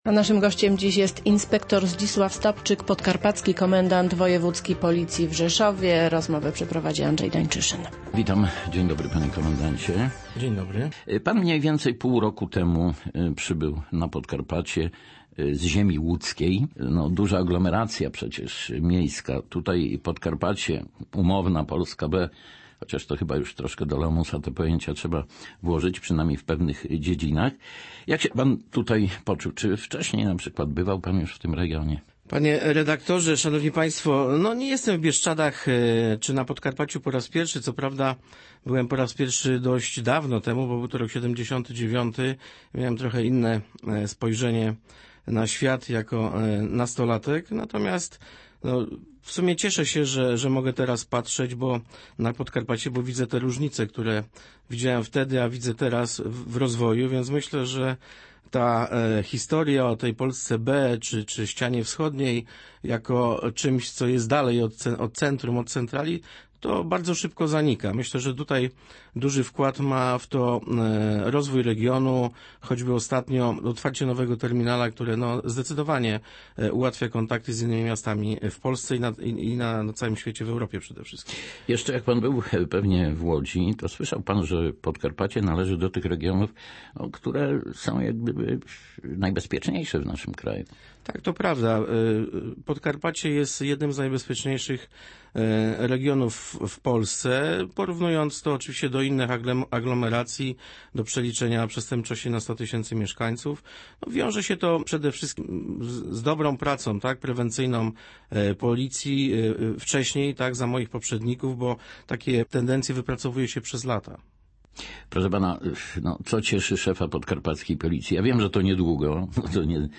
Dziś rano gościem Radia Rzeszów był inspektor Zdzisław Stopczyk, Podkarpacki Komendant Wojewódzki Policji. Pretekstem do spotkania było Święto Policji, które ustawowo przypada w dniu 24 lipca.